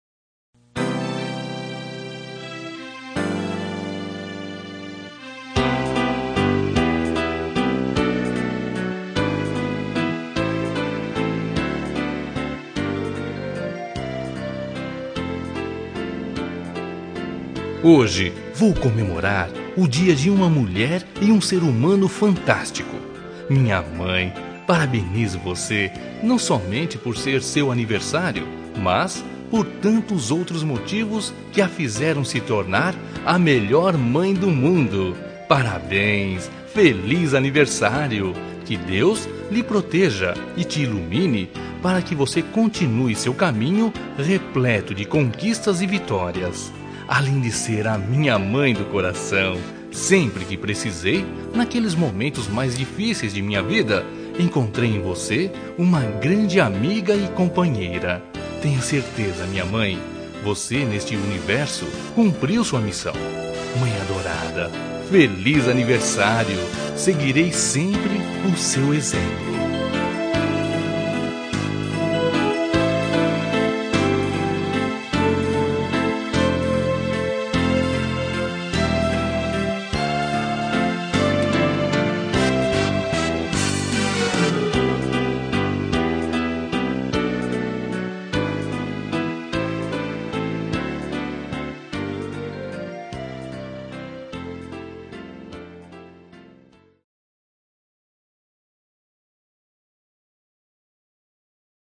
Telemensagem de Aniversário de Mãe – Voz Masculina – Cód: 1436